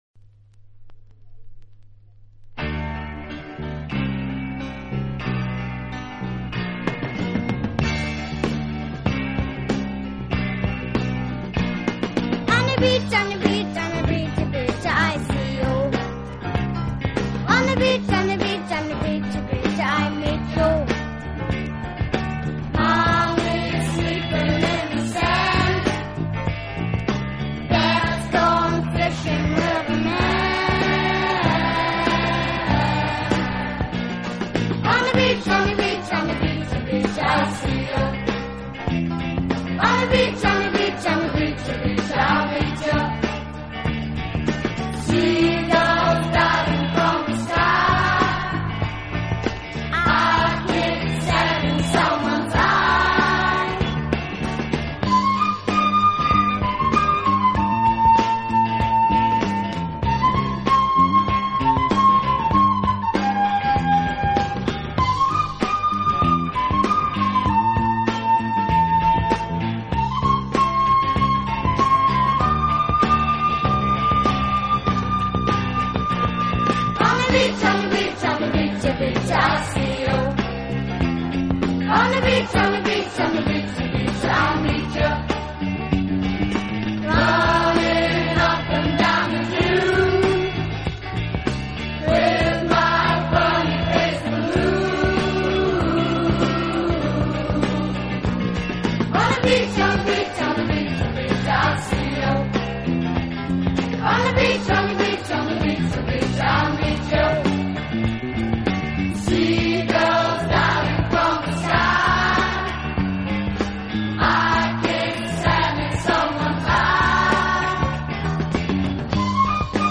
【轻音乐】
【顶级轻音乐】
引力和动人心弦的感染力。